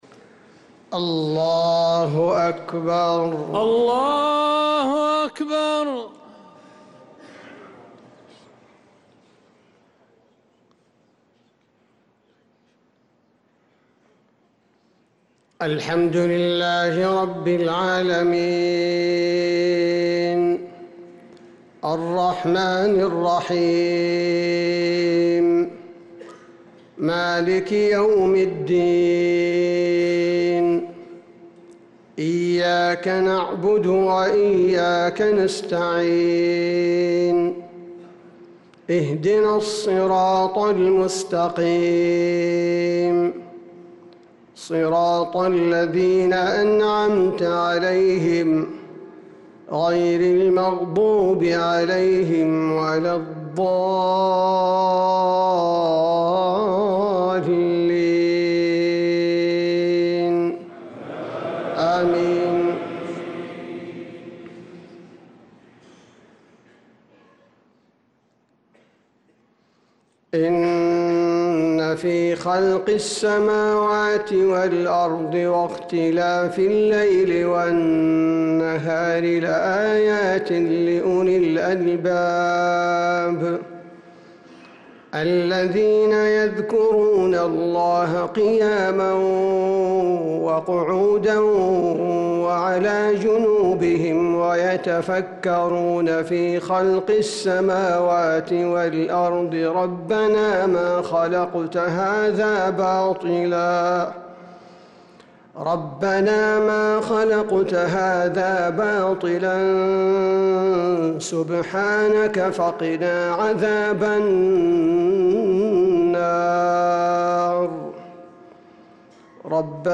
صلاة المغرب للقارئ عبدالباري الثبيتي 17 رجب 1446 هـ
تِلَاوَات الْحَرَمَيْن .